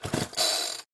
robot-hop-1.mp3